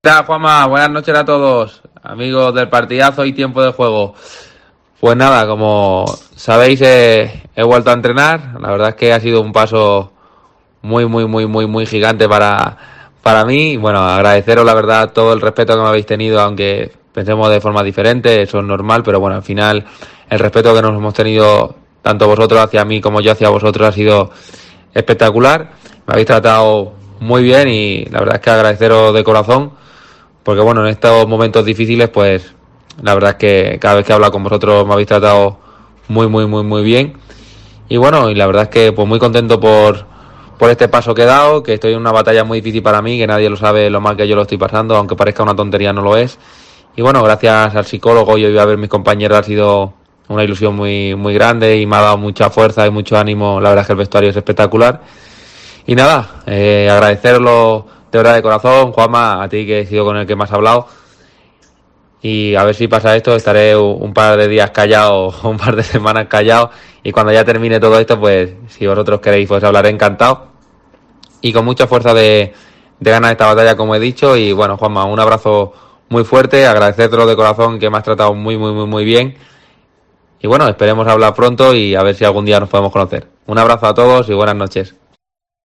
Fali manda un mensaje a El Partidazo de COPE